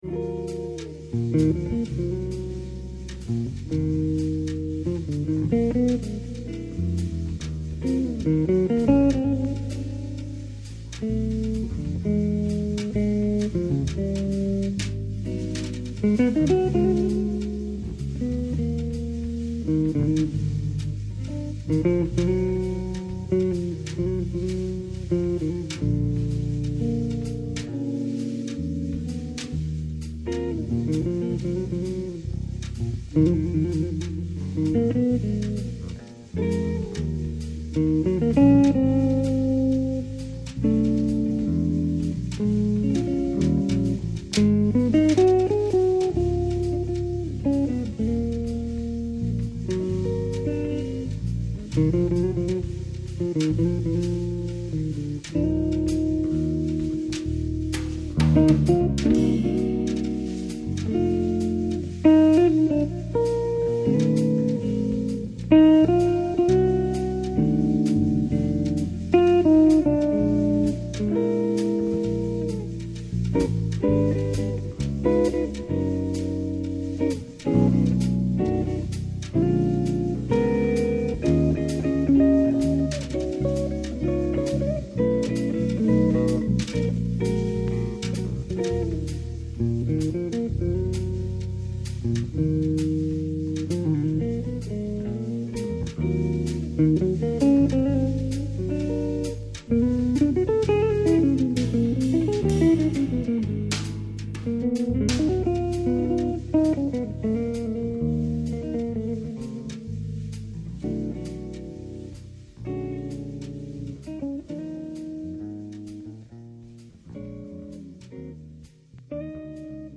Jazz Ballad